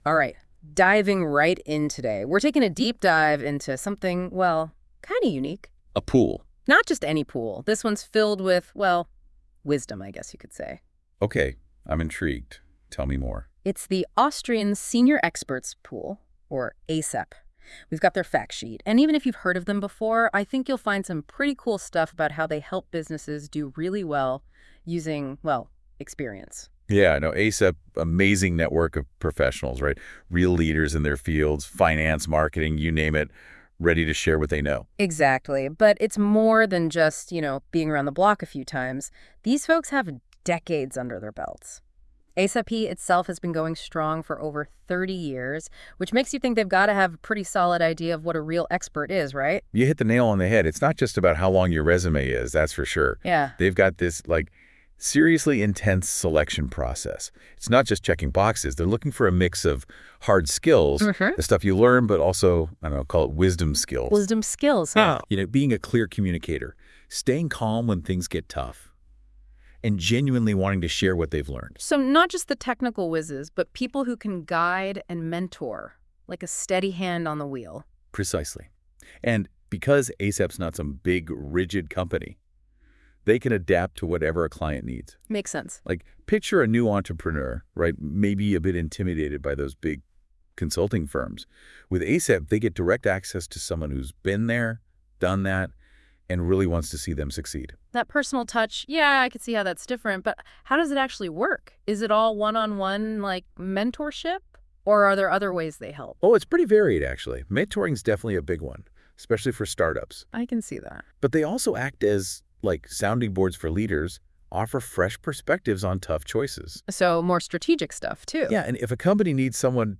Wir haben ein Experiment gestartet und mittels der KI von Google einen Podcast über uns erstellt.